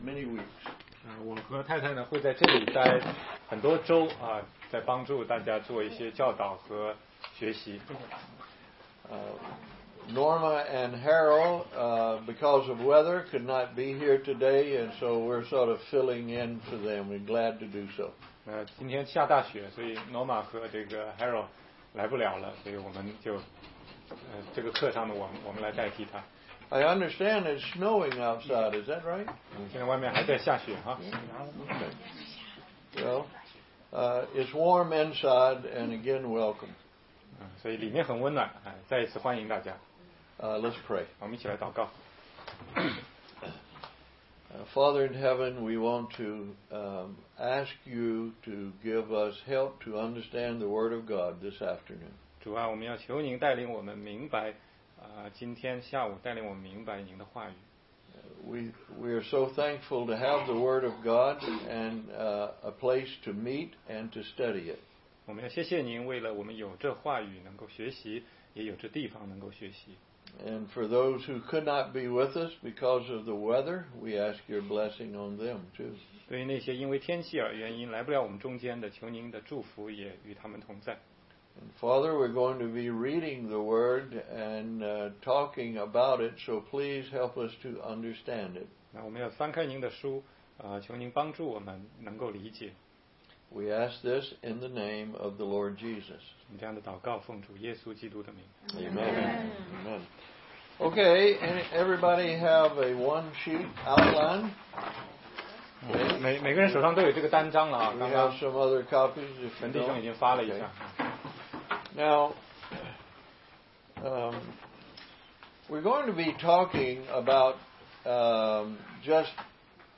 16街讲道录音 - 人生至要问题的答案系列之一